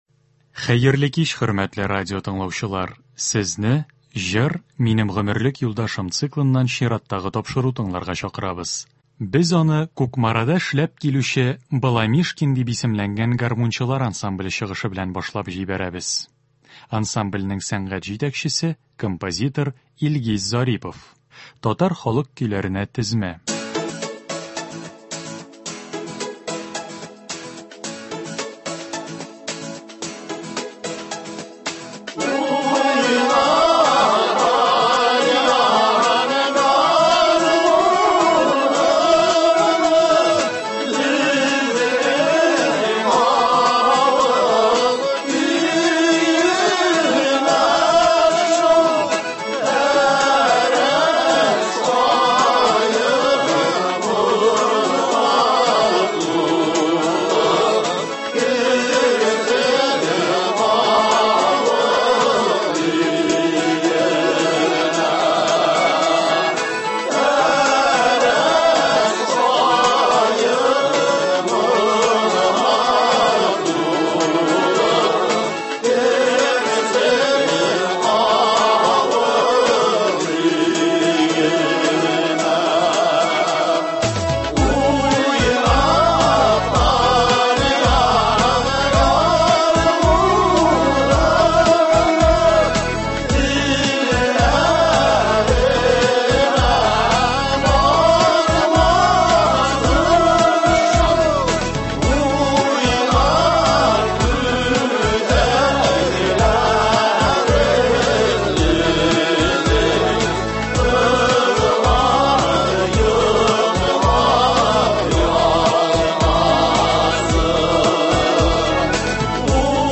Концерт. 22 июнь.